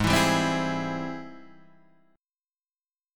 G# Augmented Major 7th